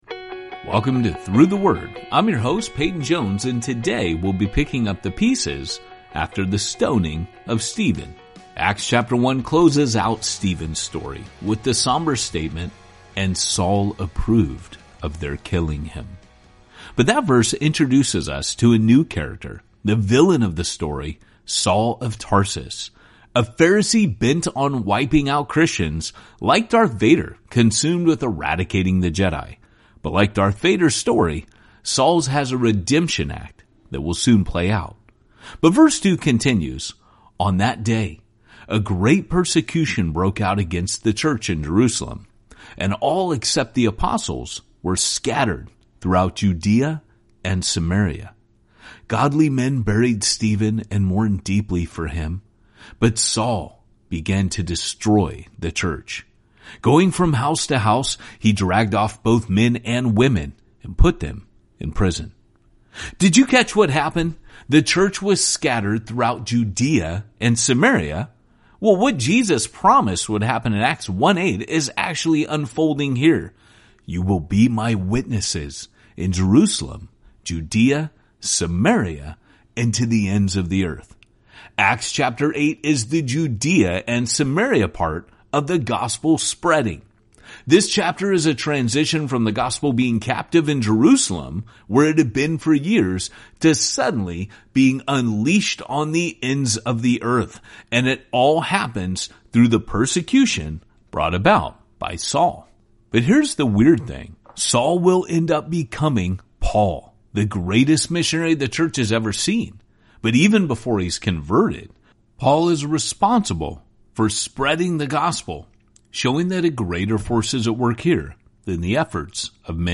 Each journey opens an epic adventure through several Bible books as your favorite teachers explain each chapter. In Journey 5, we follow Jesus through Luke, then follow the early church as God transforms their world in Acts.